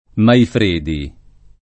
[ maifr % di ]